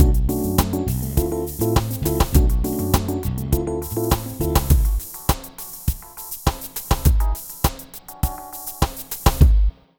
Ala Brzl 1 Fnky Full-B.wav